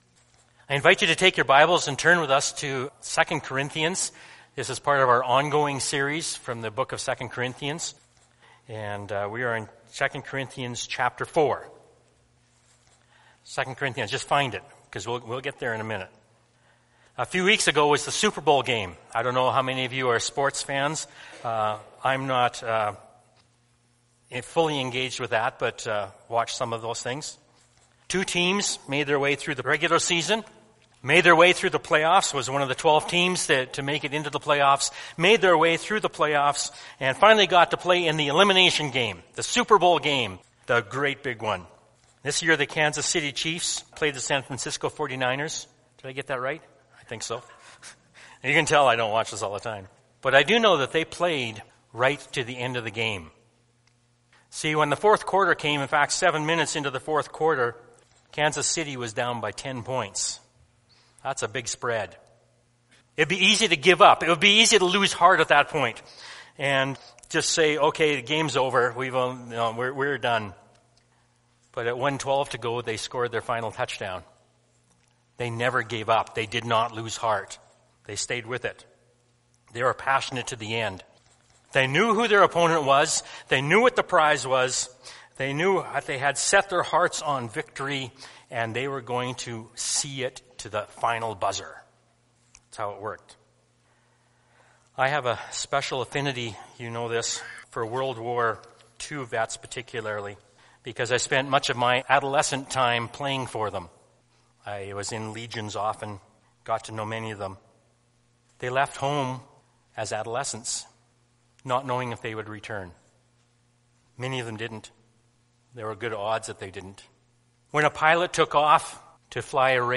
Sermons | Balmoral Bible Chapel